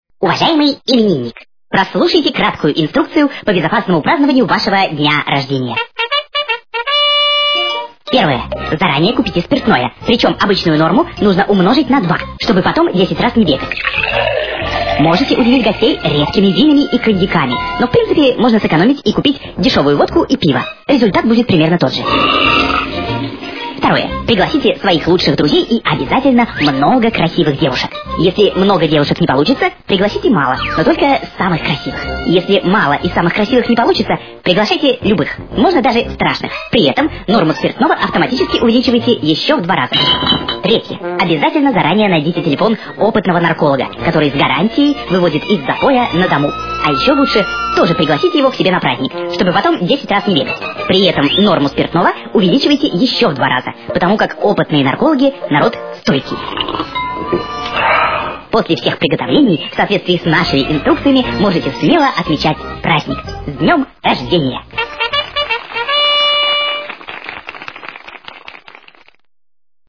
Короткое и веселое аудиопоздравление для друга